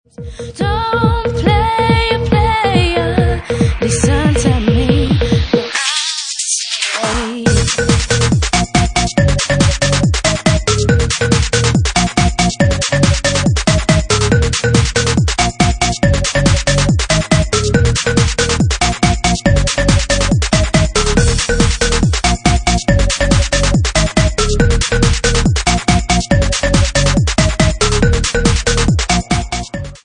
Bassline House at 142 bpm